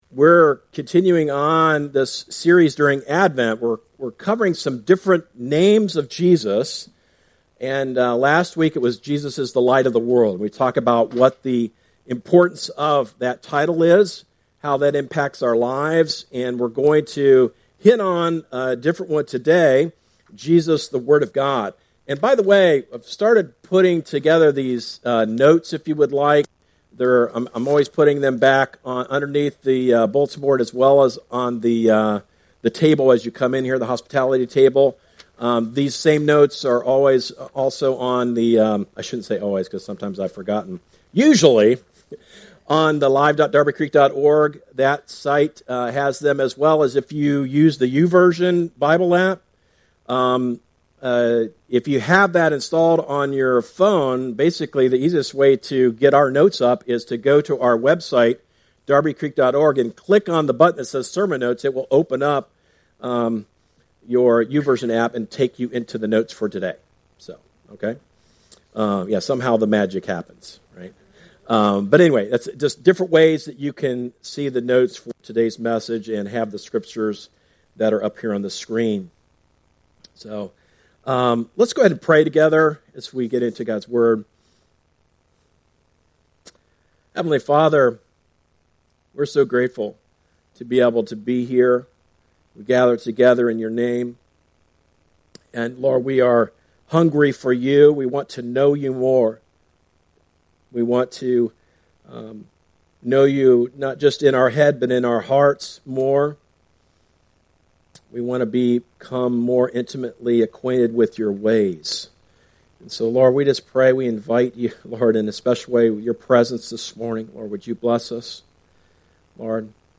Sermons – Darby Creek Church – Galloway, OH